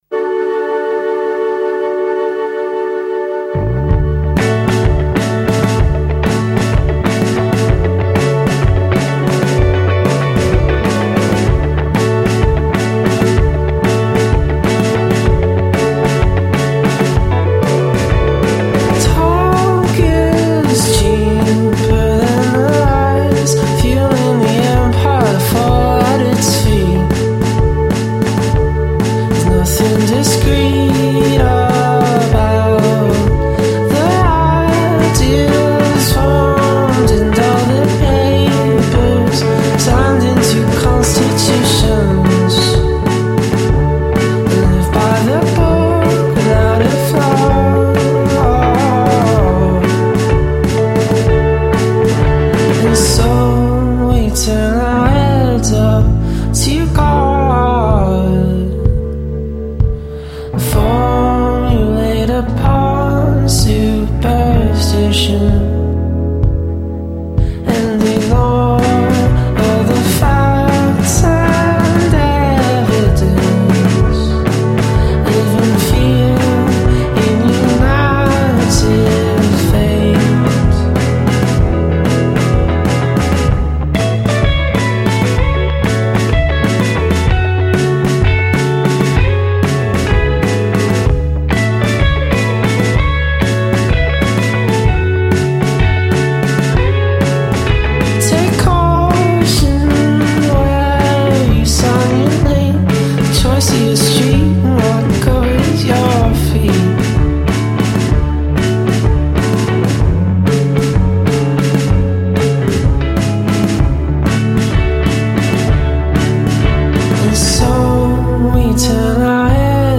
atmospheric and minimalist pop music